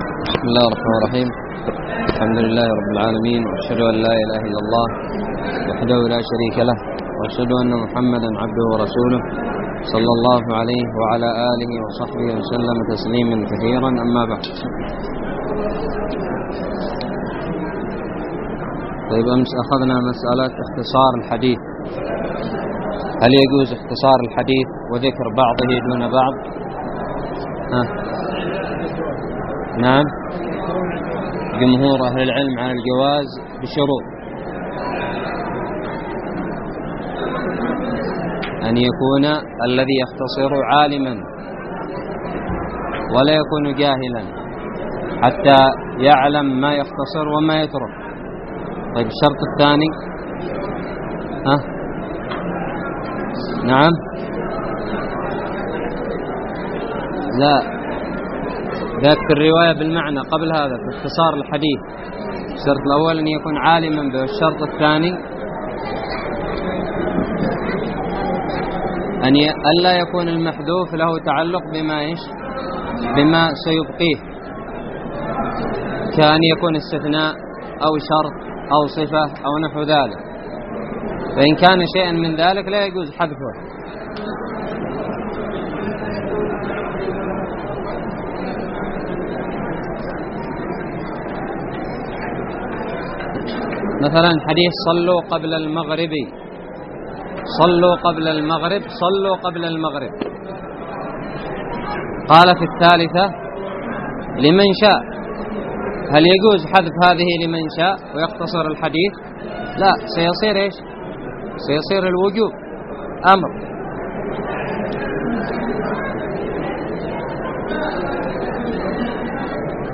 الدرس الثلاثون من شرح كتاب نزهة النظر
ألقيت بدار الحديث السلفية للعلوم الشرعية بالضالع